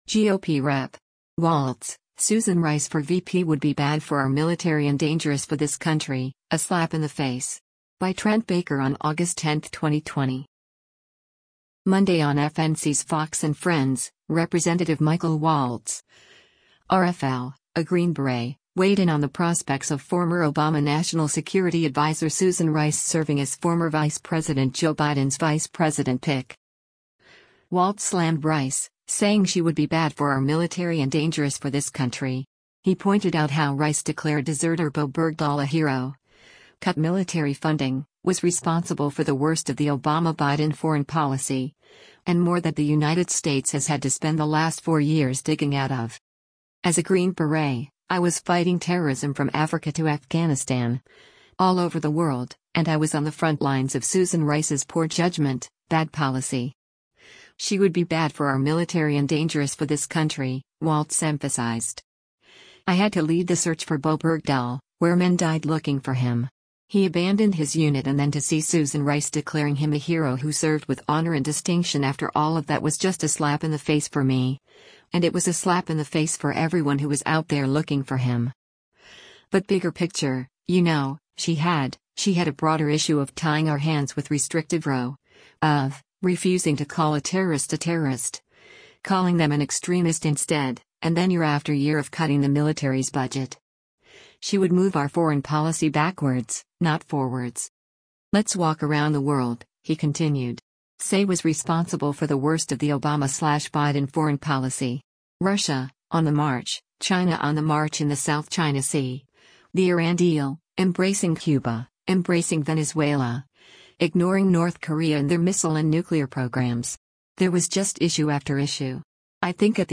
Monday on FNC’s “Fox & Friends,” Rep. Michael Waltz (R-FL), a Green Beret, weighed in on the prospects of former Obama national security advisor Susan Rice serving as former Vice President Joe Biden’s vice president pick.